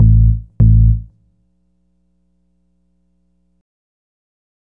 GrooveBass 11-44S.wav